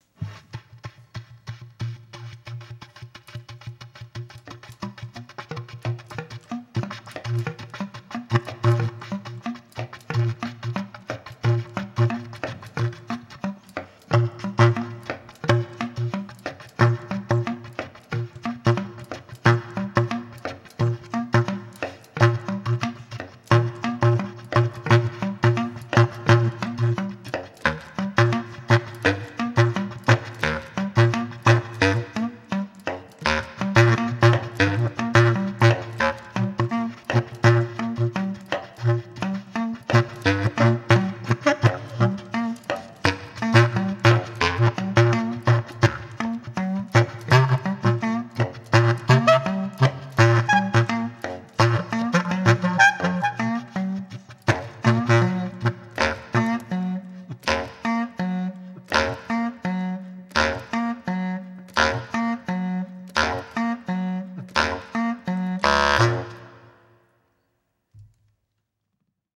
Recorded live at home in Manahattan January 29, 2011
bass clarinet, bass drum
alto clarinet, alto clarinet mouthpiece, desk items
Stereo (Pro Tools)